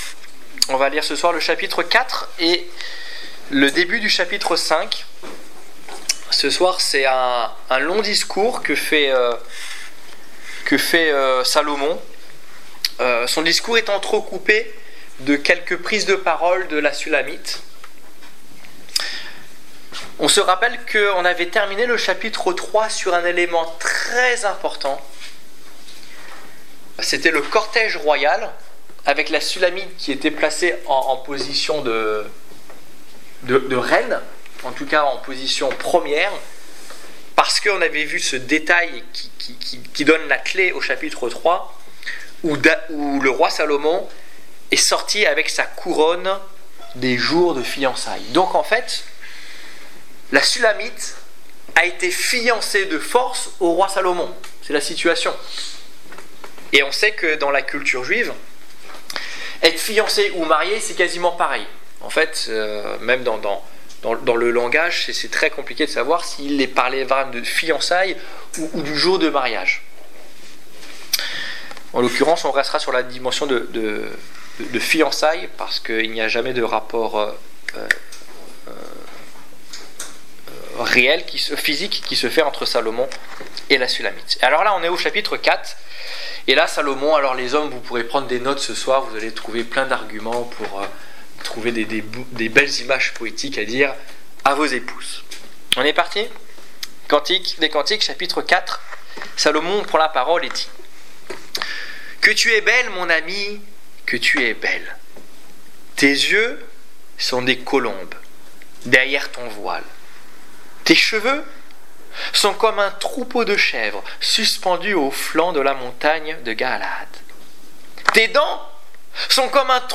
Étude biblique du 2 septembre 2015